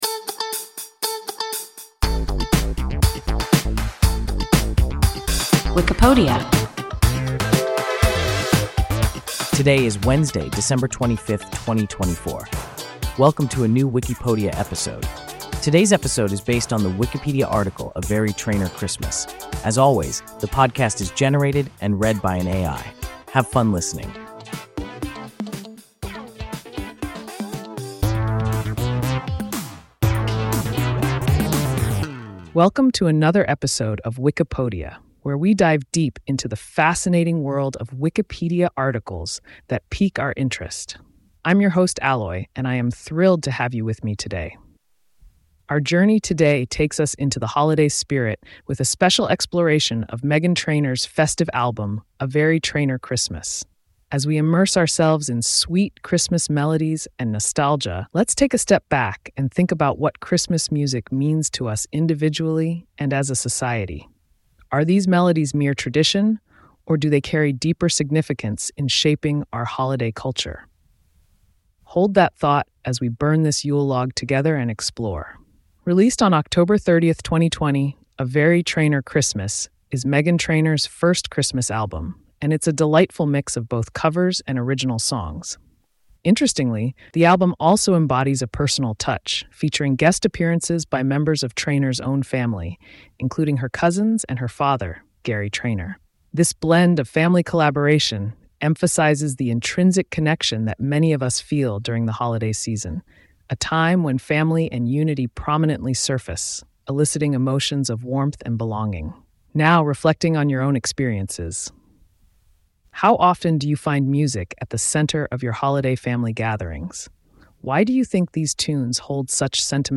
A Very Trainor Christmas – WIKIPODIA – ein KI Podcast